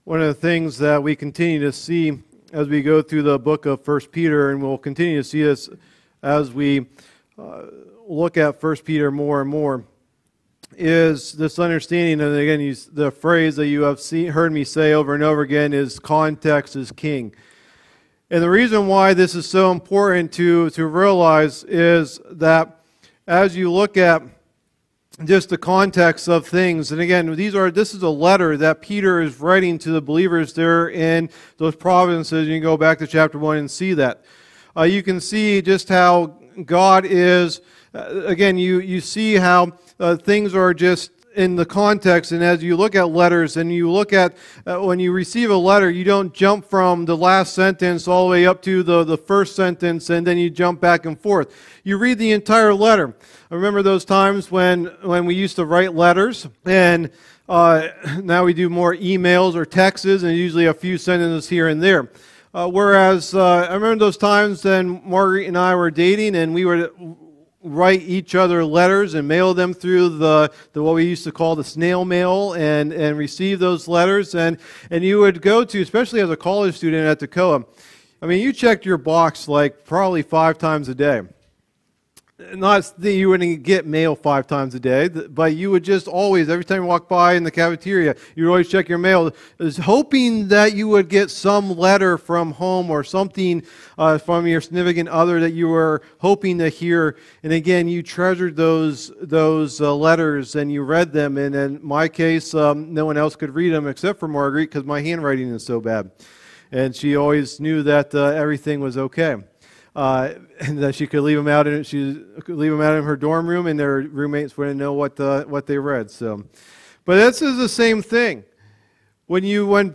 Message #13 in the "1 Peter" teaching series